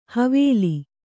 ♪ havēli